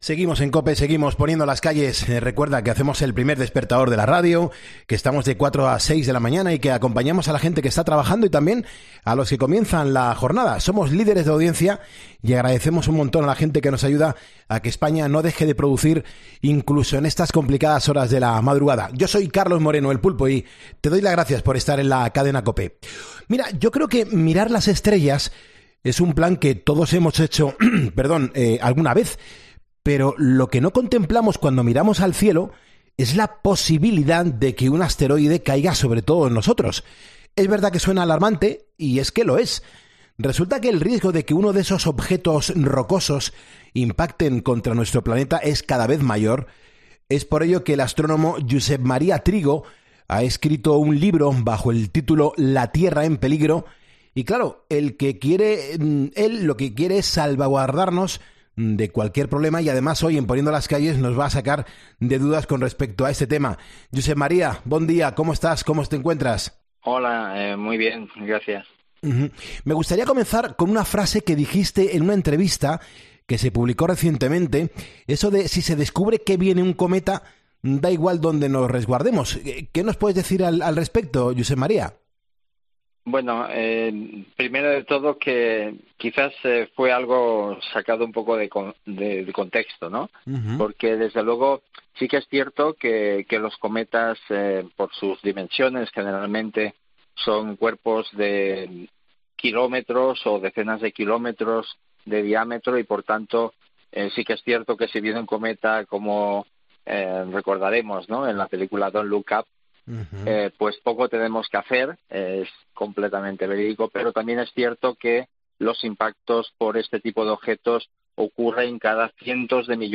ha pasado por los micrófonos de 'Poniendo las Calles' para sacarnos de dudas...